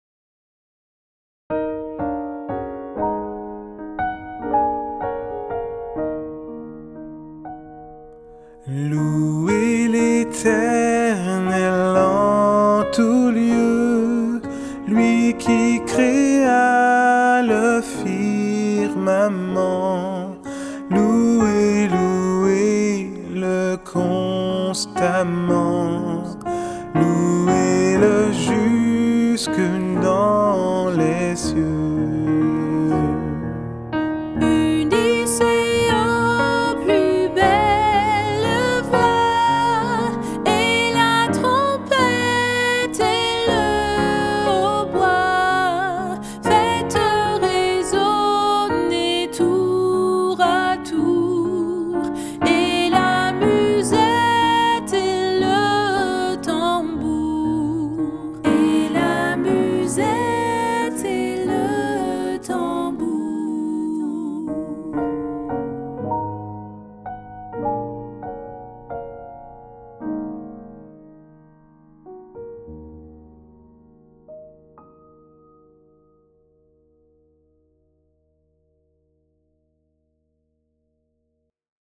Hymnes et Louange